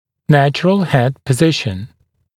[‘næʧrəl hed pə’zɪʃn] [‘нэчрэл хэд пэ’зишн] естественное положение головы (напр. при получении цефалометрических снимков)